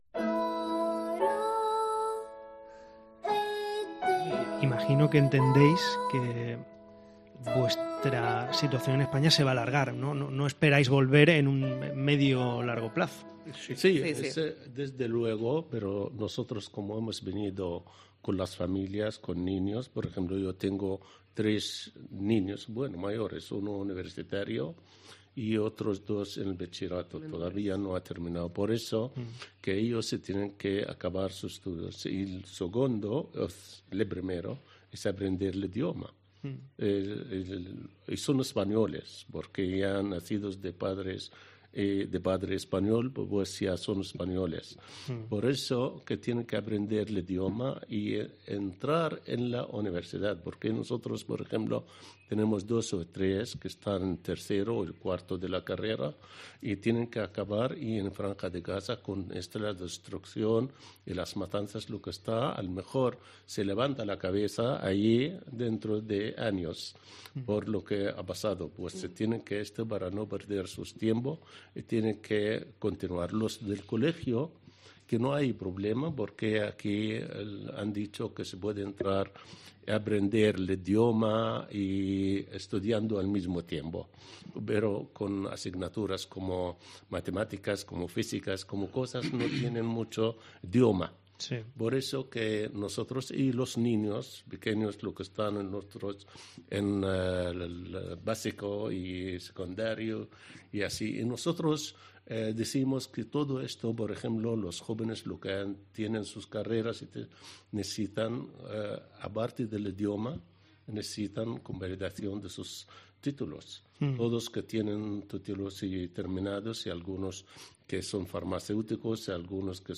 Antes de irse han conocido la emisora de Badajoz y han estado hablando con nosotros sobre sus planes de futuro.